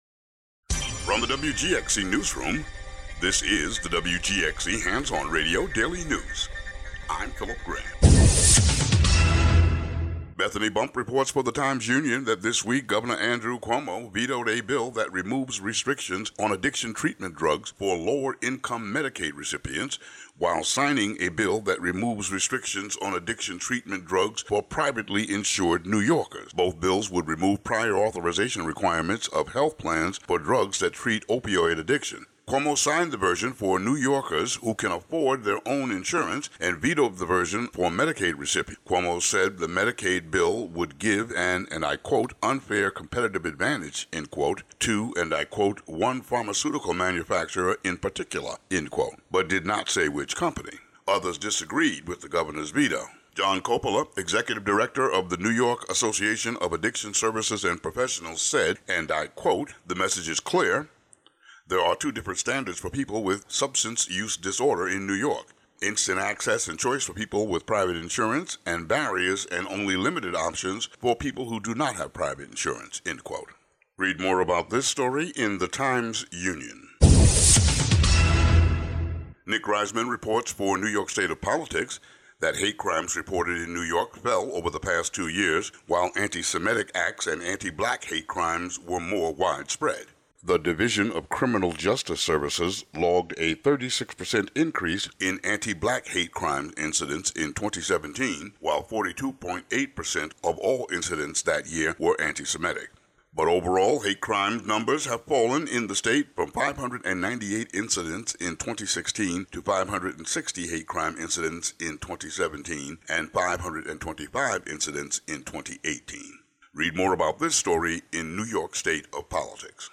The audio version of the local news for Fri., Jan. 3.